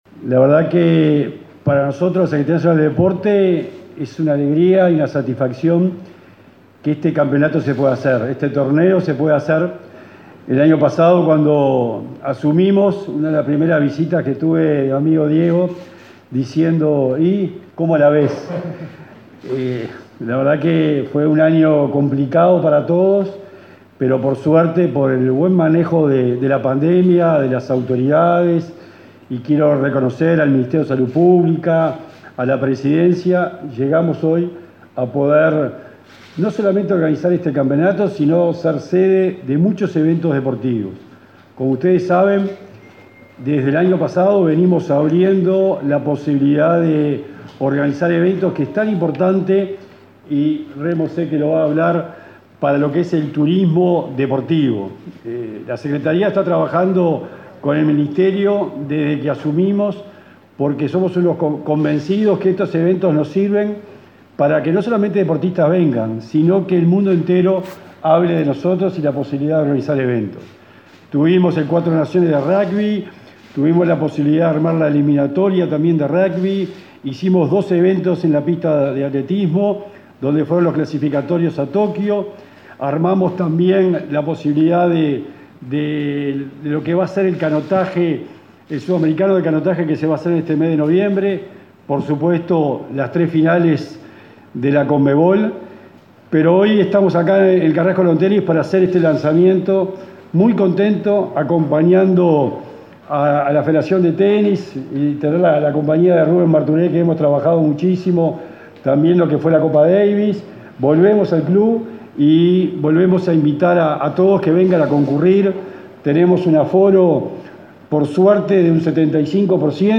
Palabras del secretario del Deporte y el subsecretario de Turismo
El secretario nacional del Deporte, Sebastián Bauzá, y el subsecretario de Turismo, Remo Monzeglio, participaron, este jueves 4 en el Carrasco Lawn